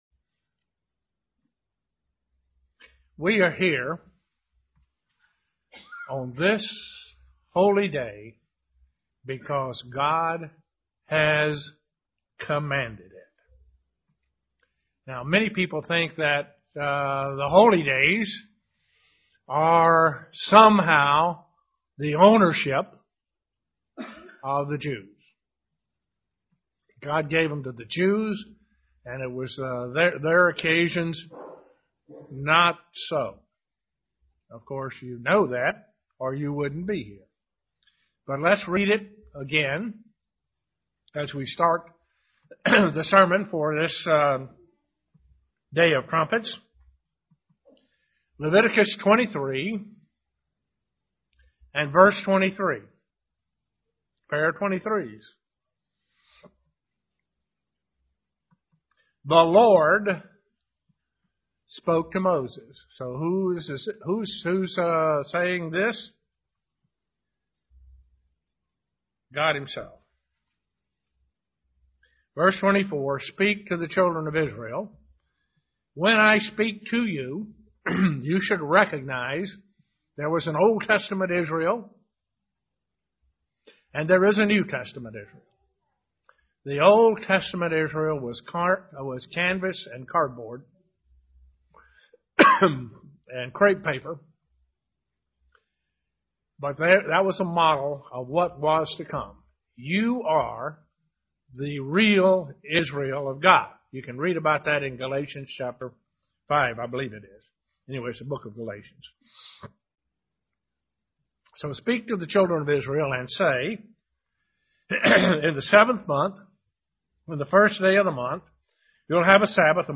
Given in Elmira, NY
UCG Sermon Studying the bible?